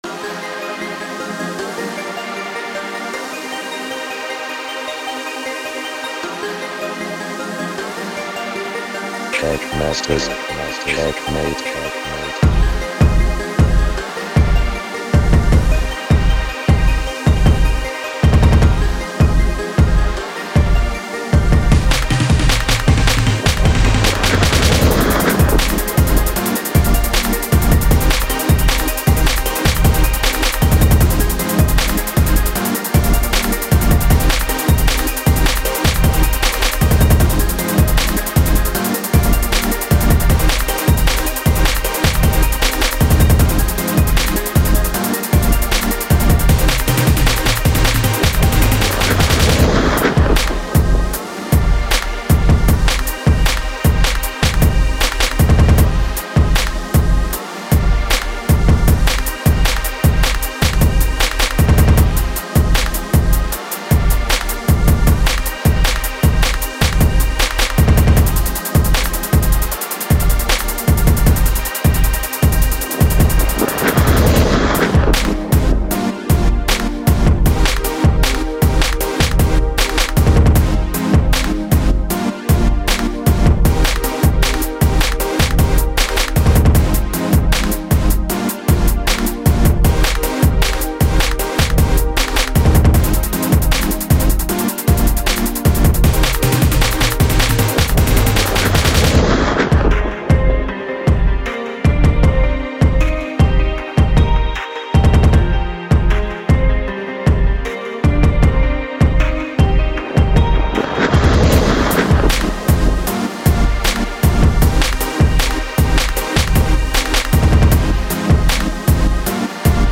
물론 더 대중적인 사운드입니다 ㅋ
BPM 155